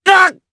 Lucias-Vox_Damage_jp_03.wav